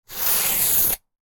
Sticky Tape Ripping Sound Effect
Sticky-tape-ripping-sound-effect.mp3